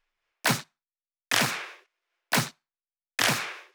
VFH3 128BPM Resistance Kit 6.wav